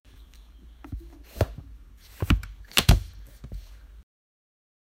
Engaging Sound Effects
A rich audio experience enhances the gameplay, providing immediate feedback and creating an immersive atmosphere.
factory-card-sound-effects.mp3